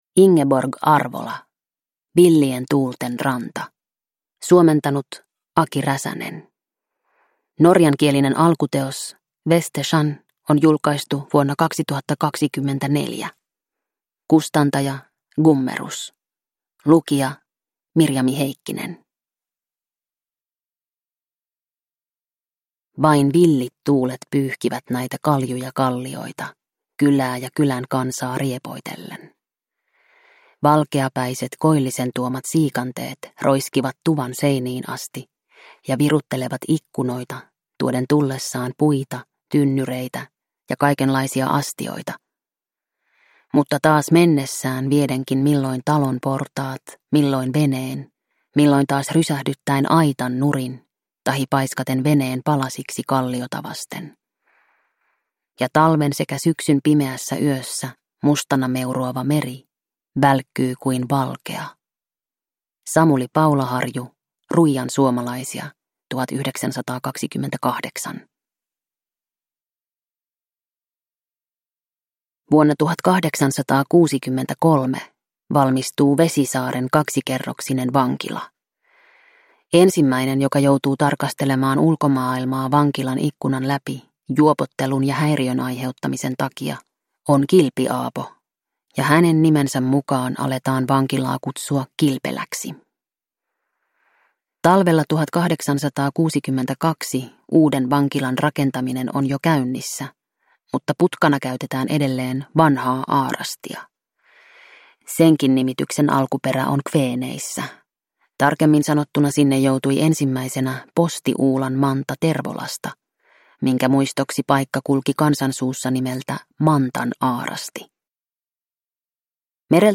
Villien tuulten ranta – Ljudbok